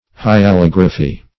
Hyalography \Hy`a*log"ra*phy\, n.